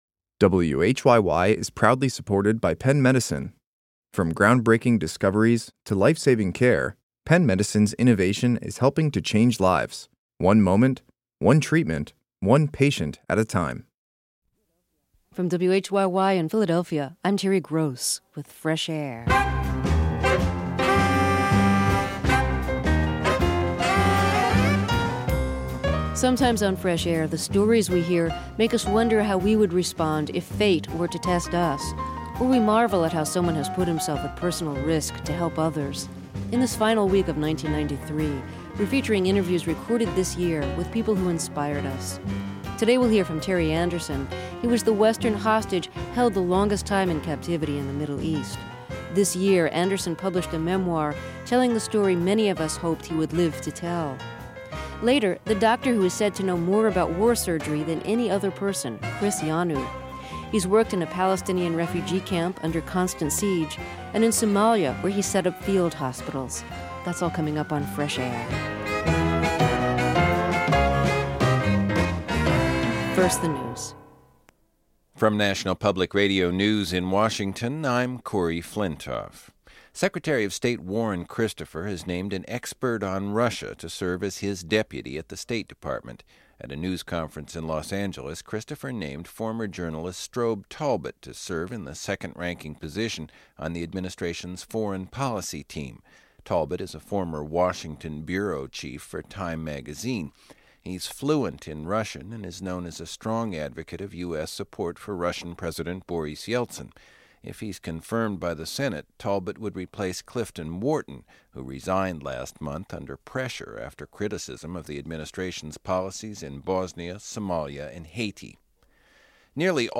Terry Gross is the host and an executive producer of Fresh Air, the daily program of interviews and reviews.
Former hostage and journalist Terry Anderson. For seven years he was held hostage in Lebanon, the longest held western hostage.